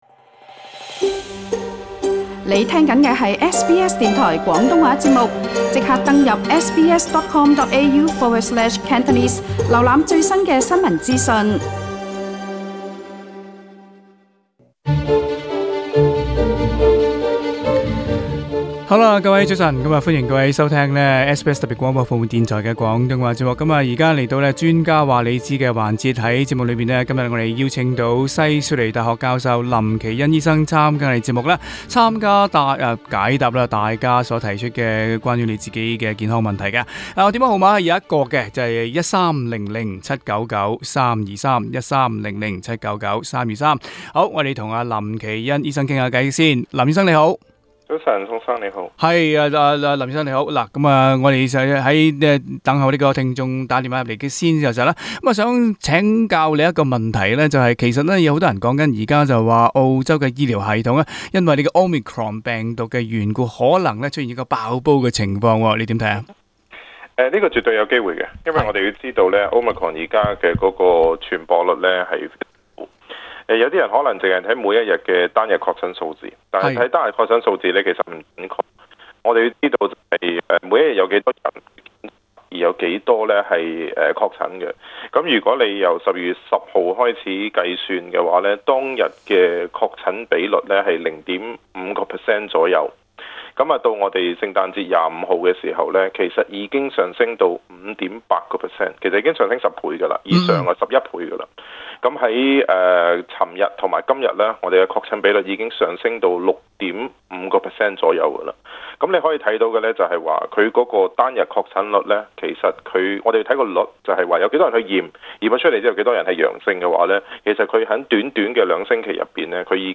並即場解答聽眾有關健康的問題。